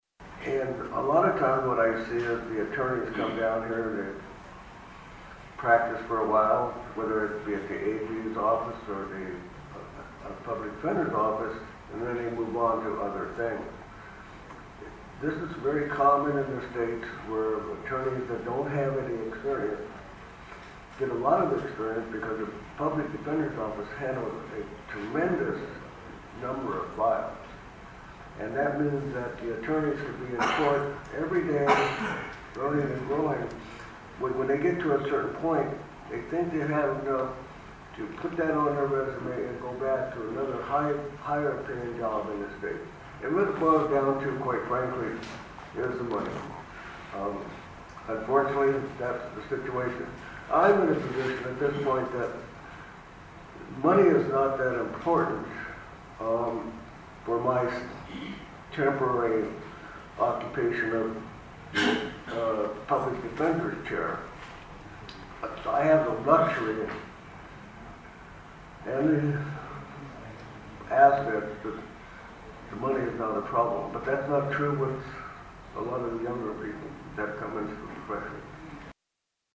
During his confirmation hearing in the Senate, Vargas was asked about the difficulty in getting attorneys here. This was his response.